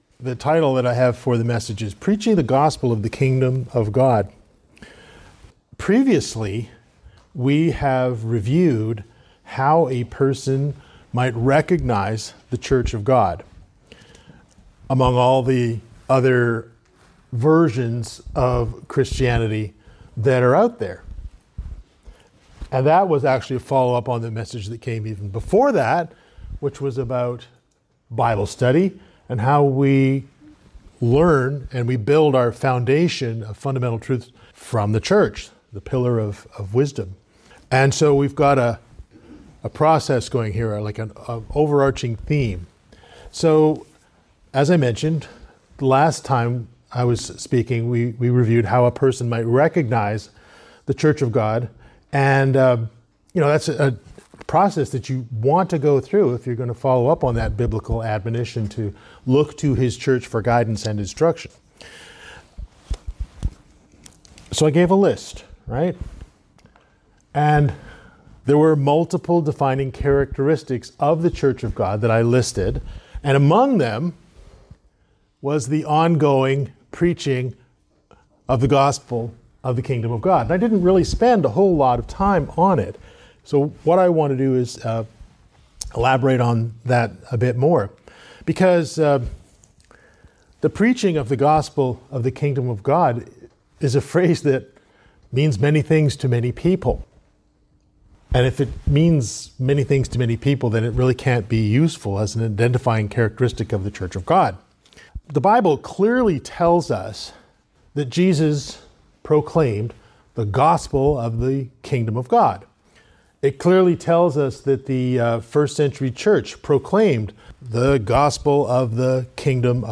This is part of a series of related sermons that build on each other.